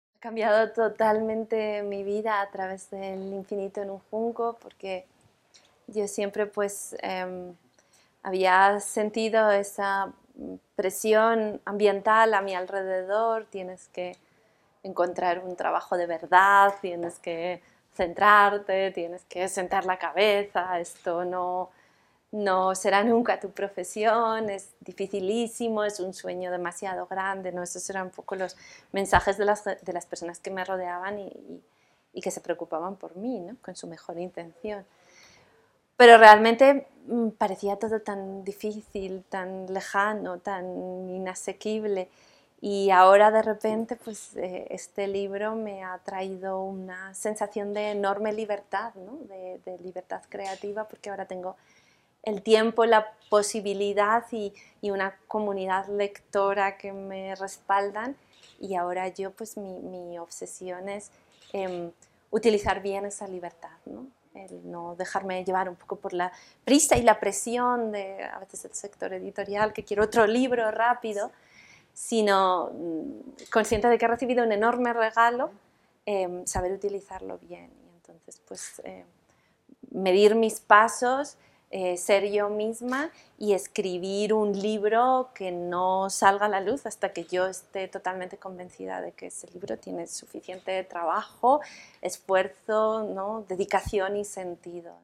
La sala Nezahualcóyotl, del Centro Cultural Universitario de la UNAM, fue el espacio en el que la escritora española Irene Vallejo, autora de El infinito en un junco: la invención de los libros en el mundo antiguo, tuvo un conversatorio con universitarias y universitarios, a quienes llamó a ser arqueólogos de las palabras de las mujeres, y rescatarlas de la sombra y el silencio.